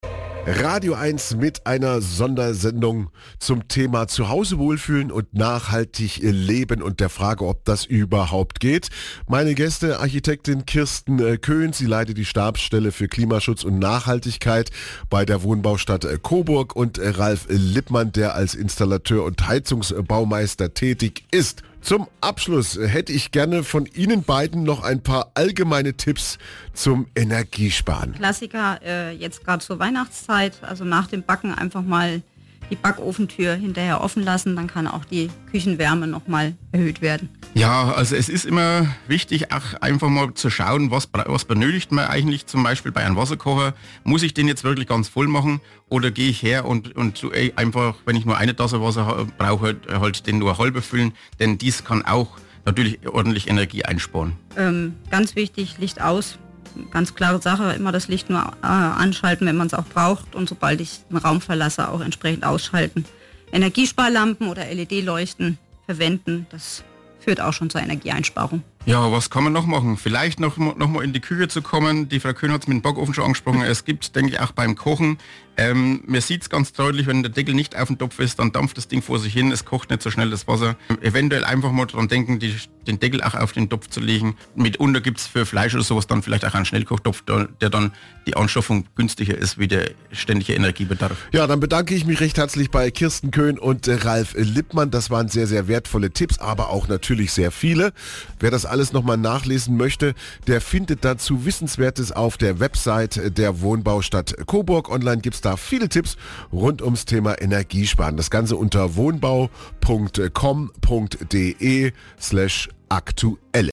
Radio Sondersendung – „Zu Hause wohlfühlen und nachhaltig Leben. Geht das überhaupt?“